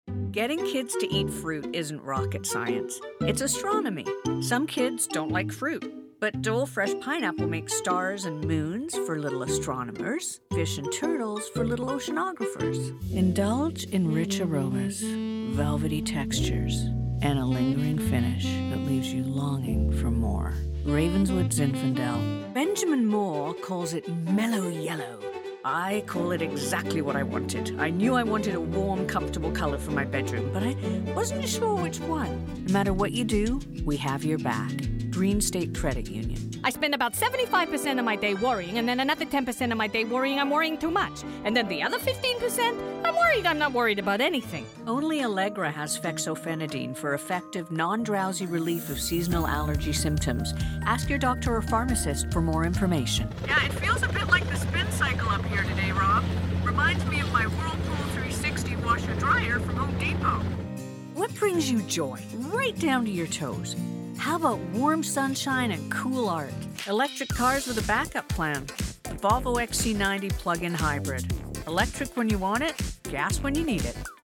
canadian, authentic british dialects
Middle Aged